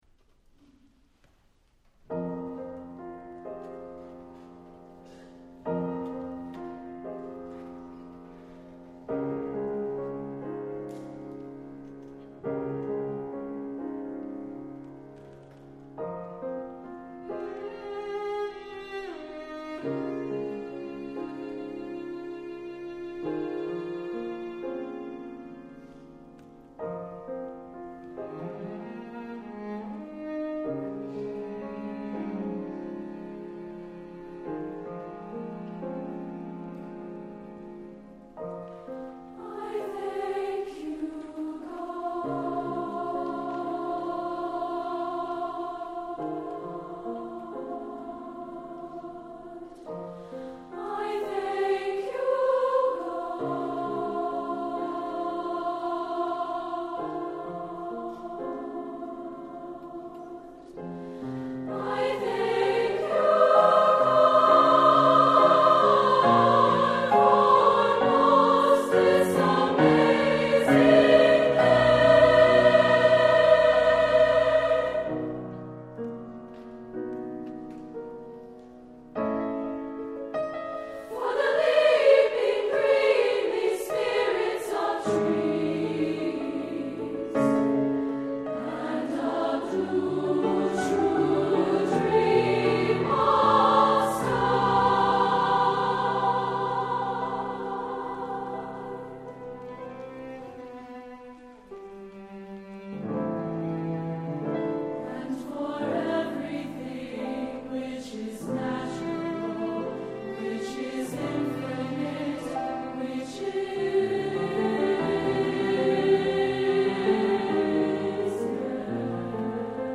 Voicing: SSAA and Piano